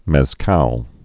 (mĕz-kăl)